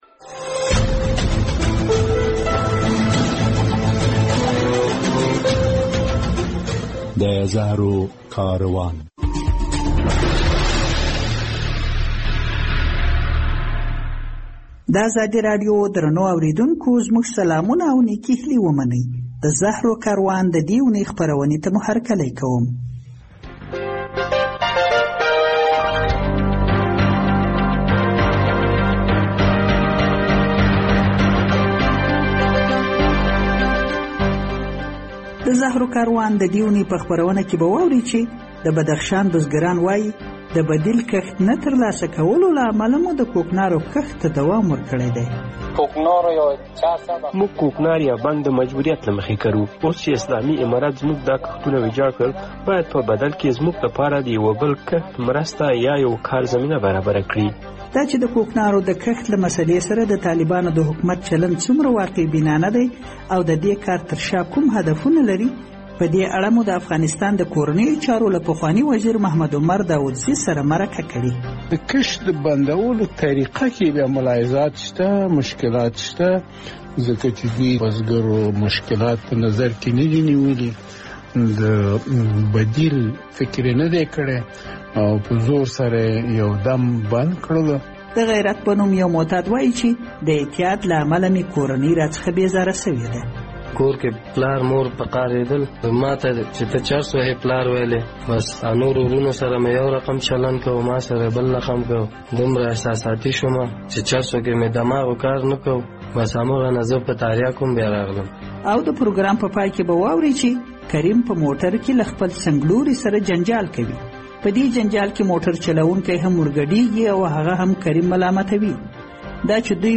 د زهرو کاروان د دې اوونۍ په خپرونه کې به واورئ چې بزګرو ته د کوکنارو د بدیل نه ورکولو هغوی د طالبانو د کوکنارو اړولو پروګرام پر وړاندې اعتراض ته اړ کړي چې په یوه مرکه کې همدا موضوع څېړل شوې ده.